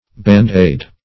band-aid - definition of band-aid - synonyms, pronunciation, spelling from Free Dictionary
Aid\ (b[a^]nd"[=a]d`) n. [from a Trademark.]